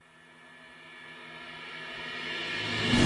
STAHL鼓组 " Crash2 Hi
描述：头顶（AB）Sennheiser e614